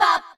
rahRahSisBoomBaBoomgirls5.ogg